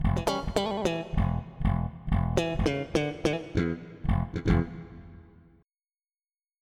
slapbass.mp3